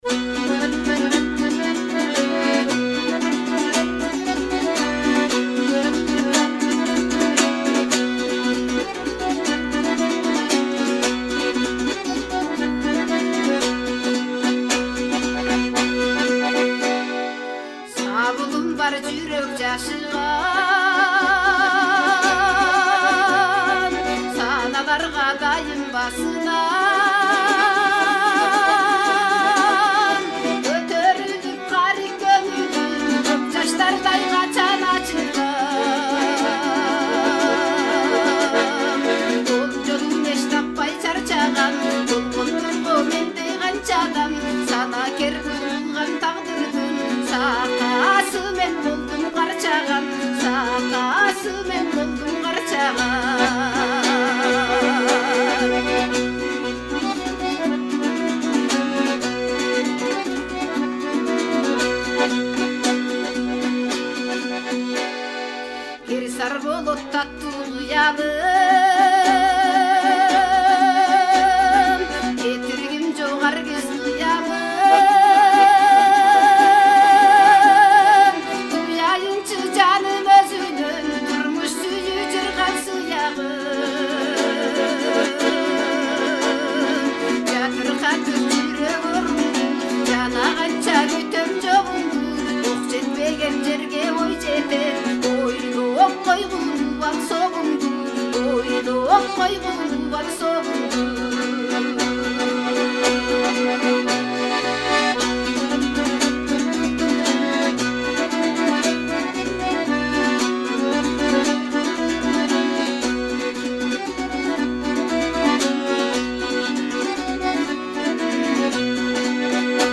мукам үнүн комуздун коңур добушуна коштоп, терме айтуу